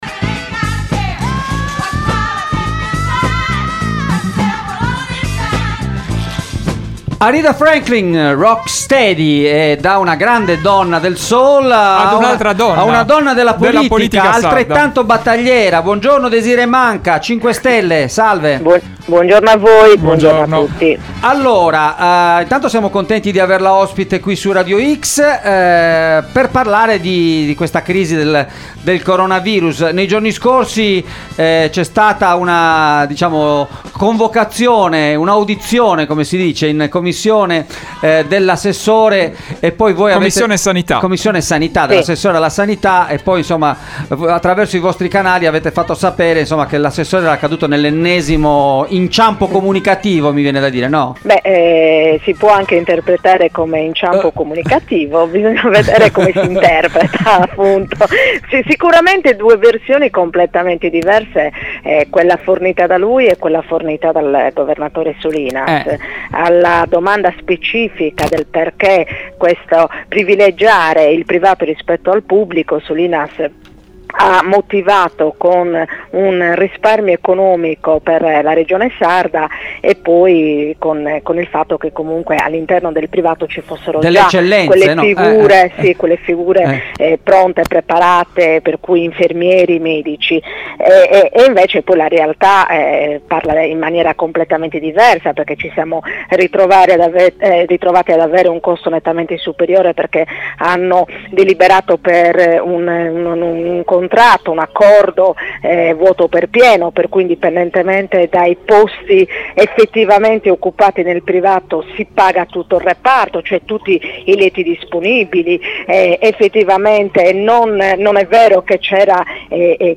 consigliera regionale del Movimento 5 Stelle è intervenuta ai microfoni di Extralive mattina